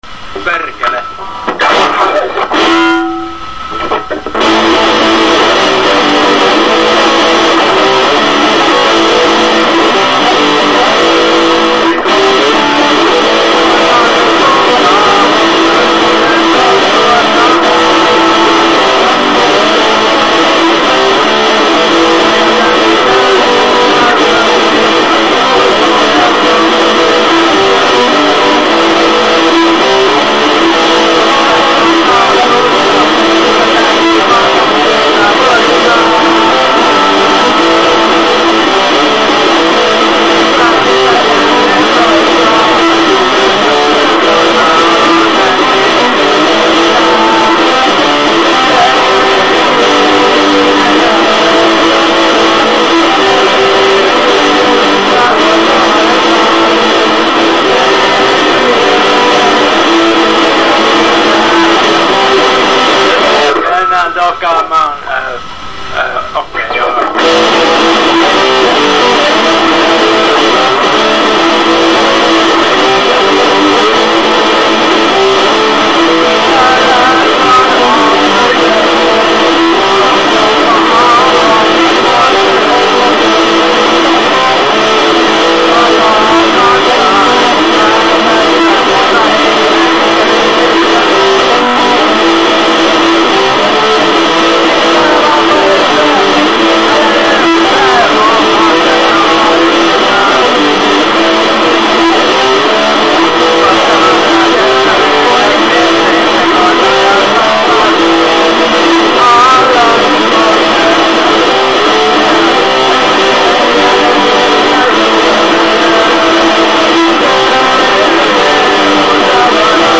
Some really noizy and cool wave-files done by me (in first take with fuck-ups and whatevers included):
ELECTRIC
IN YOUR FACE!!! Total punk by the best punkband ever, A.L.K.O. from Finland, in Finnish...